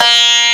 SITAR2.WAV